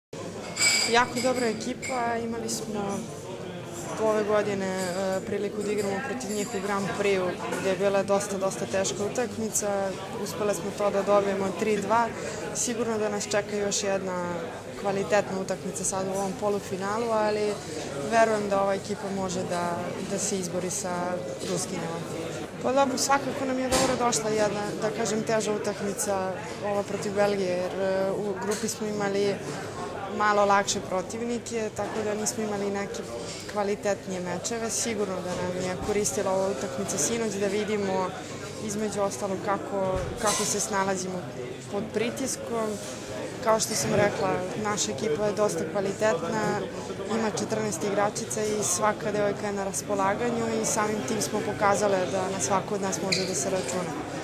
IZJAVA SUZANE ĆEBIĆ